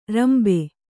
♪ rambe